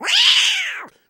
Звуки агрессивной кошки
Яростный звук кошки